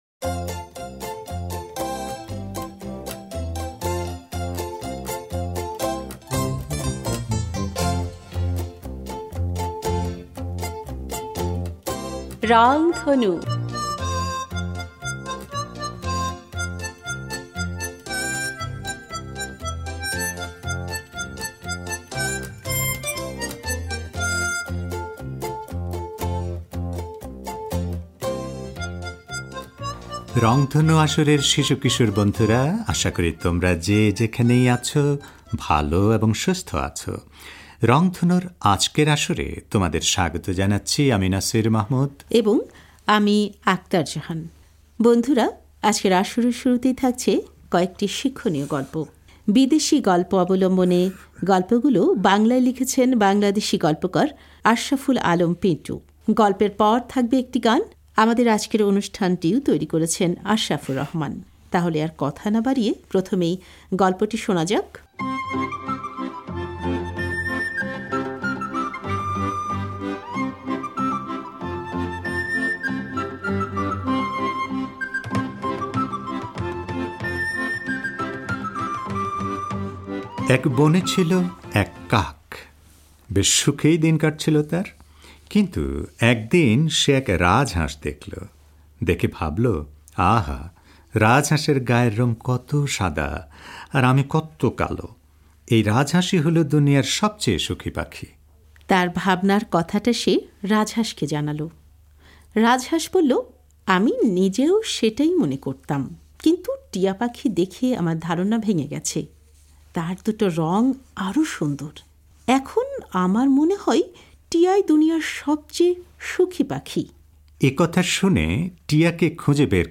গল্পের পর থাকবে একটি গান।